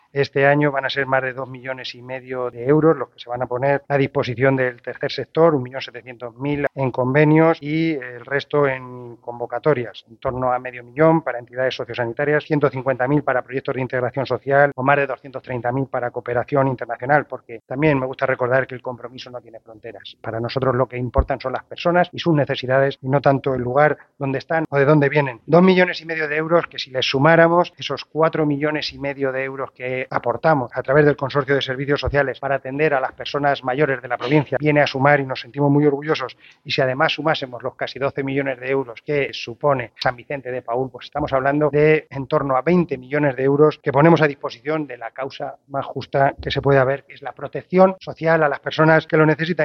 Tras las firmas y cerrando el acto, ha tomado la palabra el presidente.
Corte-de-Cabanero-dando-las-cifras-que-la-Diputacion-invierte-en-la-proteccion-social-de-las-personas.mp3